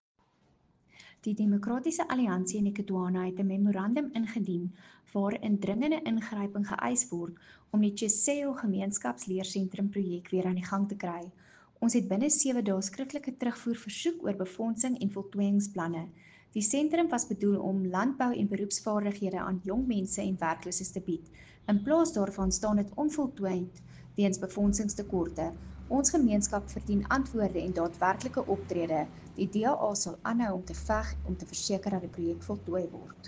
Afrikaans soundbites by Cllr Anelia Smit and Sesotho soundbite by Cllr Diphapang Mofokeng.